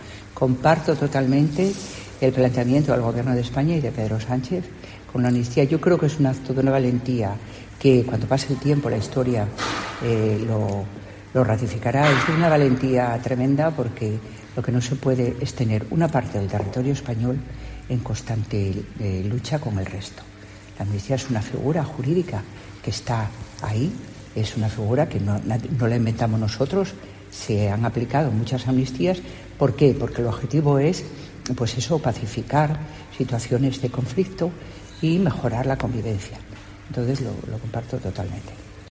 La Delegada del Gobierno en Asturias asegura que no se puede tener a una parte del territorio "en constante lucha" con el resto